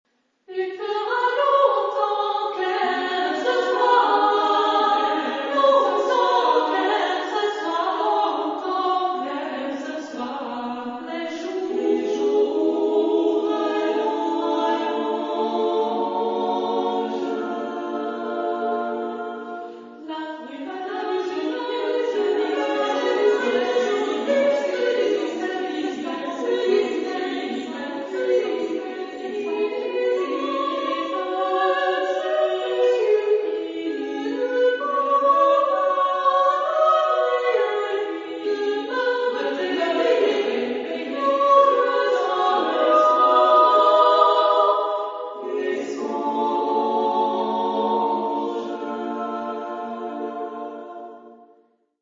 Genre-Style-Forme : Profane ; Poème ; contemporain
Type de choeur : SSAA  (4 voix égales de femmes )
Tonalité : polymodal